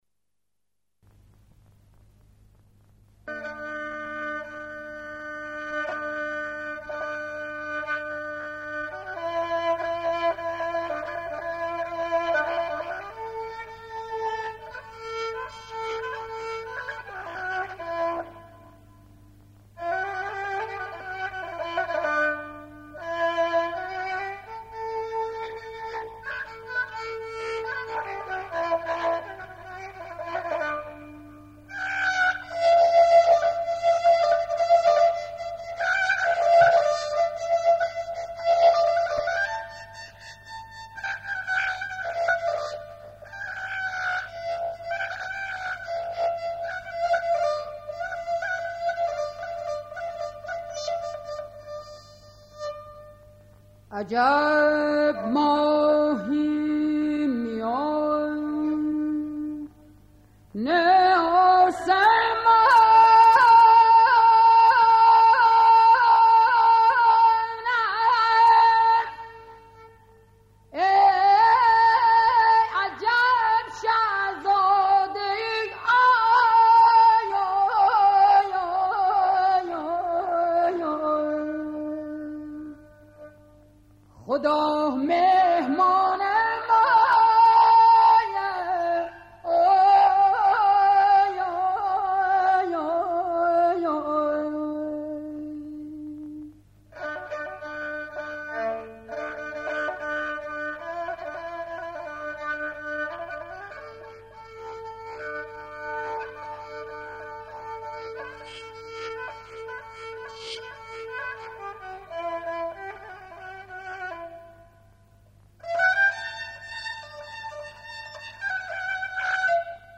• دانلود آهنگ لری